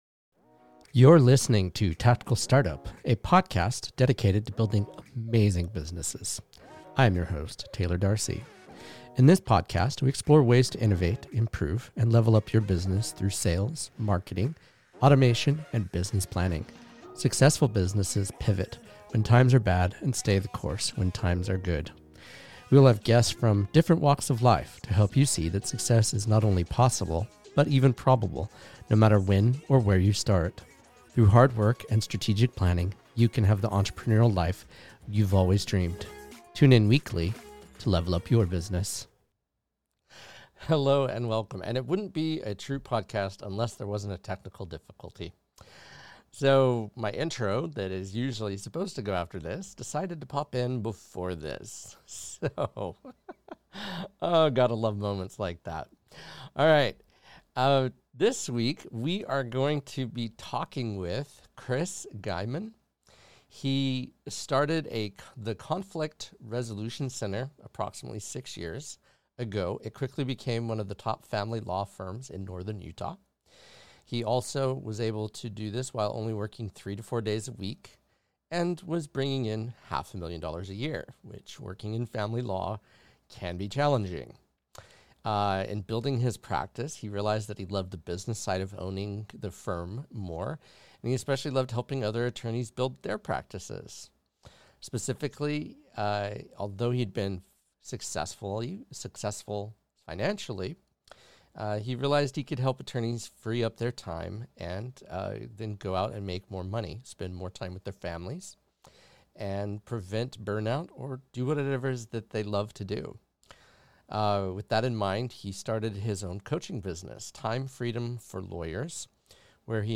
Shure SM7B Microphone
Rode Rodecaster Pro